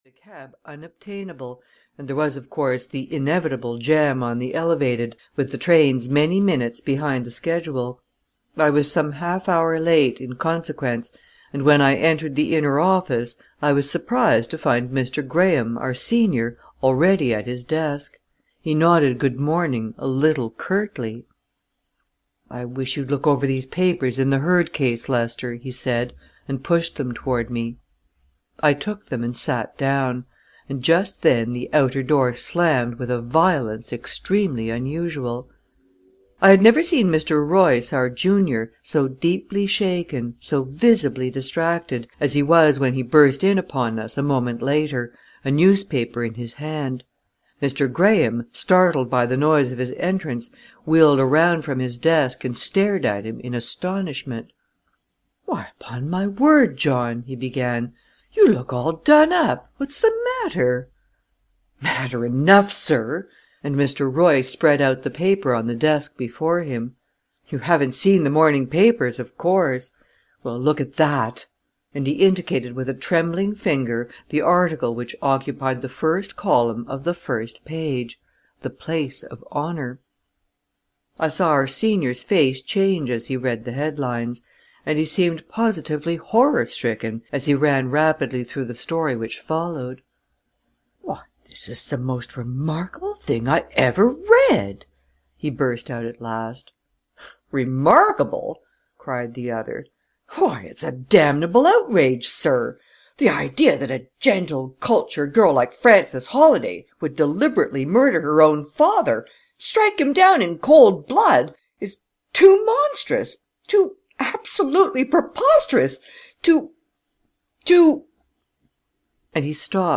The Holladay Case (EN) audiokniha
Ukázka z knihy